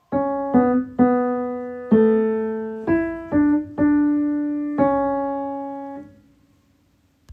ALTO II: Hymn #130: Be Thou Humble
Audition Key: A major* |
Starting Pitch: C# | Sheet Music
alto-2-hymn-130-a-major-m4a